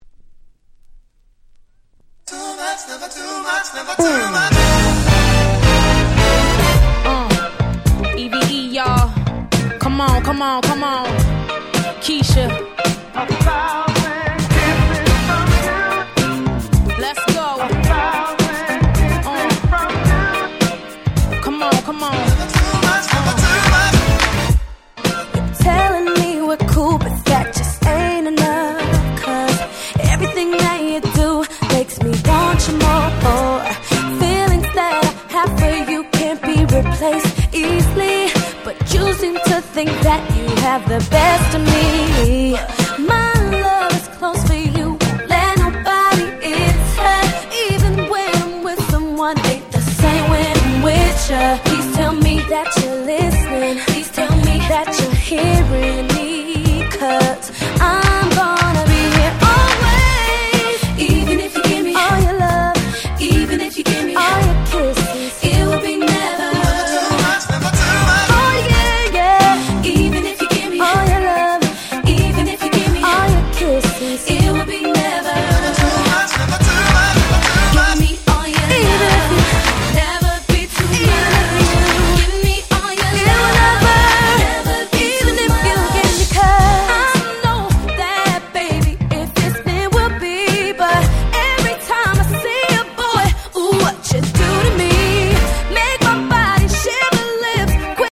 03' Very Nice R&B !!